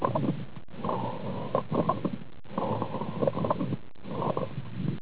Bronchial breath sounds, rhonchi and late inspiratory crackles (are heard) in the area of the right mid-anterior and right mid-lateral lung fields.